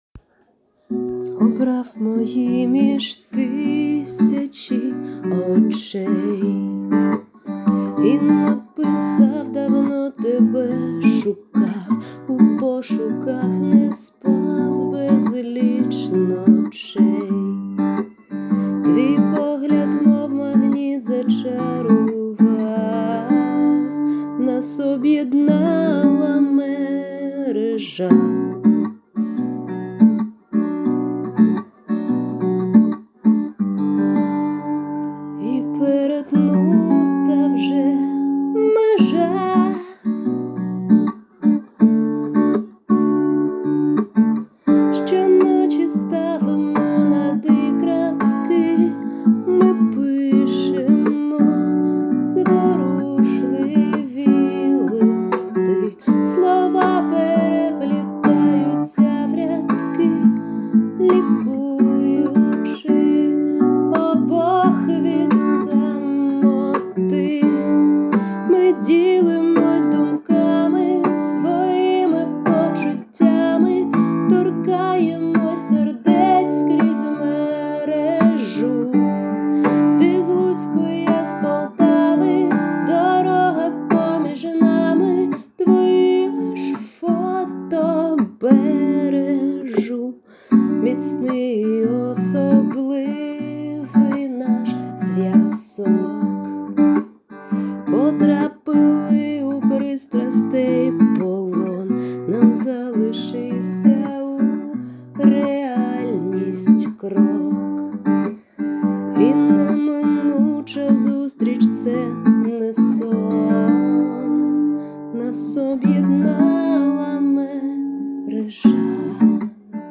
ТИП: Пісня
СТИЛЬОВІ ЖАНРИ: Ліричний
Дуже гарне виконання. 39 39 39 Чарівний і ніжний голос give_rose hi